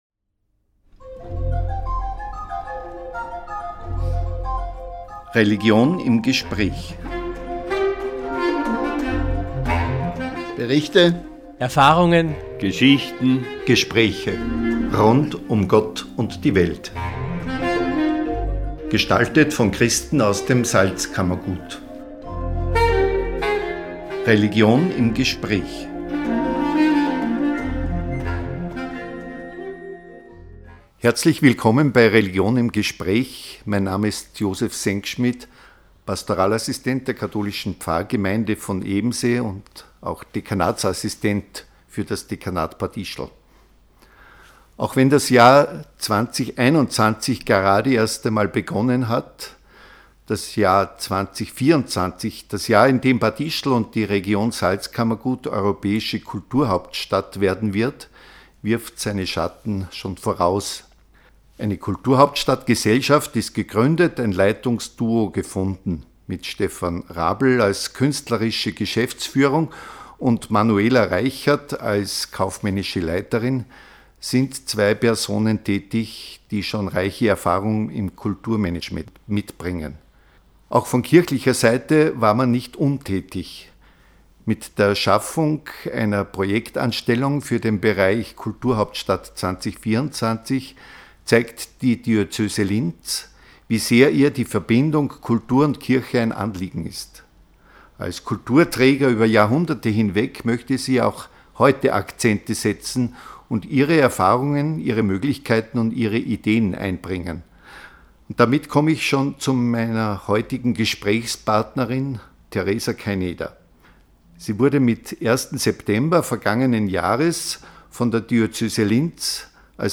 Radiosendung "Religion im Gespräch"